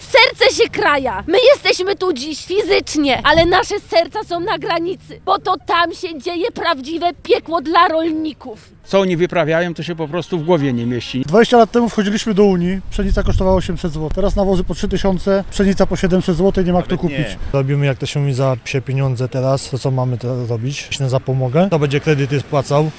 Mówią protestujący rolnicy i przyznają, że obecna sytuacja sprawia, że młode pokolenia rezygnują z pracy w rolnictwie.